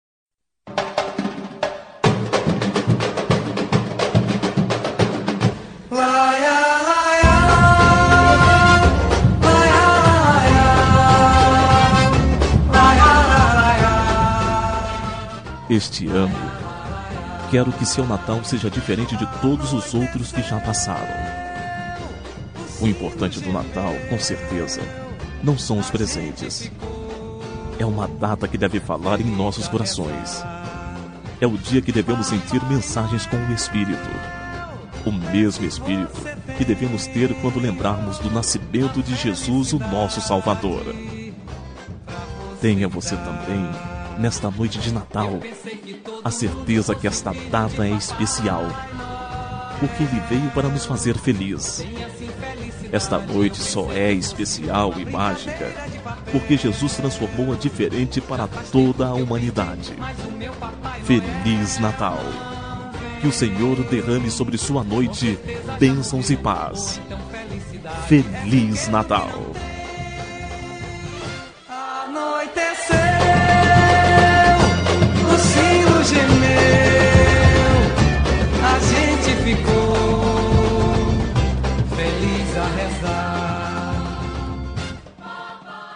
Natal Pessoa Especial – Voz Masculina – Cód: 347923